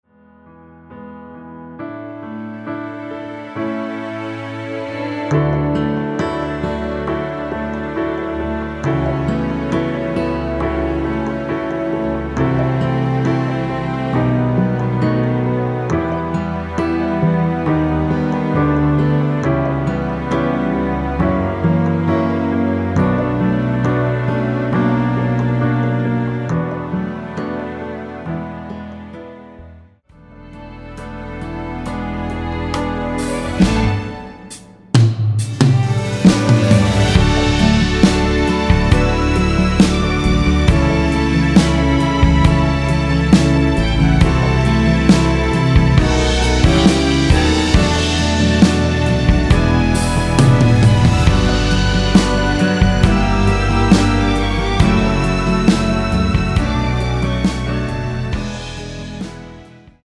전주가 길어서 미리듣기는 중간 부분 30초씩 나눠서 올렸습니다.
원키에서(-2)내린 MR입니다.